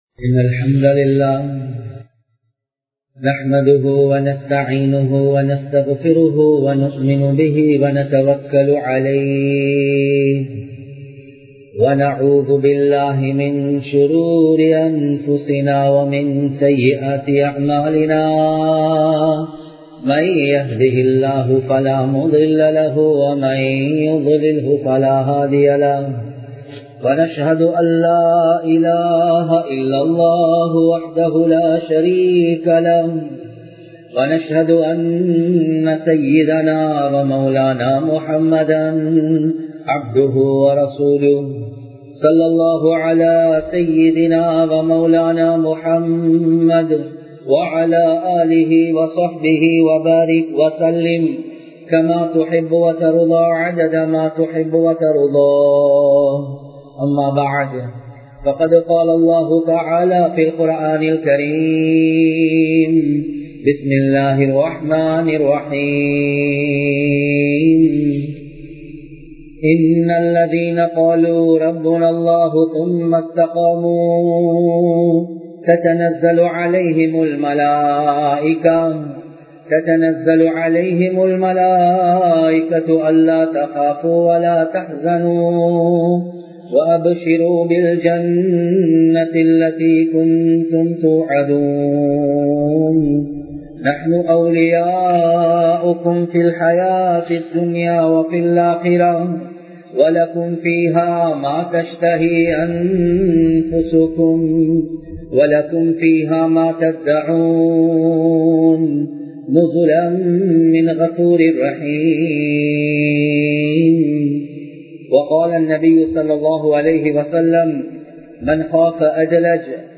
இஸ்லாம் கூறும் வியாபாரி (The businessman to whom islam says) | Audio Bayans | All Ceylon Muslim Youth Community | Addalaichenai
Thaqwa Jumua Masjith